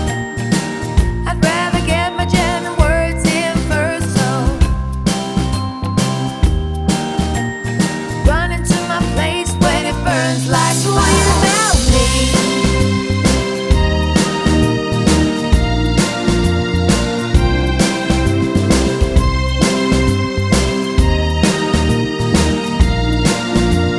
One Semitone Up Pop (2010s) 3:24 Buy £1.50